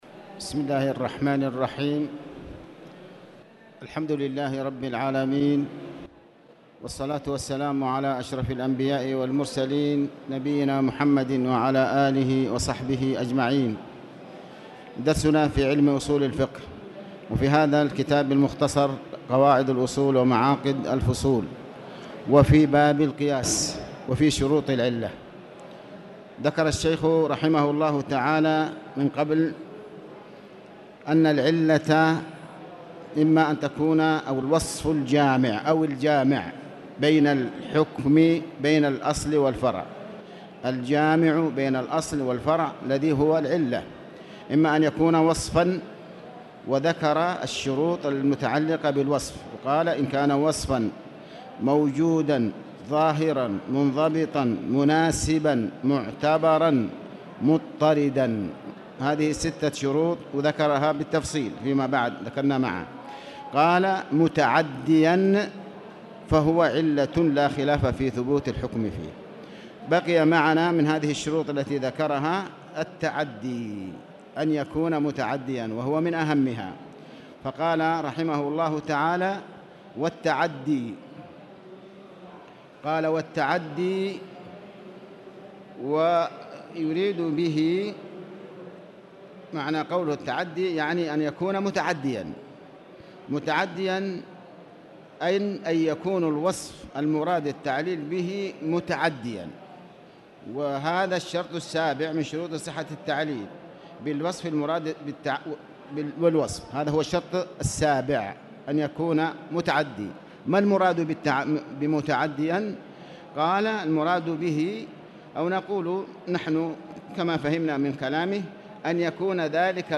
تاريخ النشر ١٣ ربيع الثاني ١٤٣٨ هـ المكان: المسجد الحرام الشيخ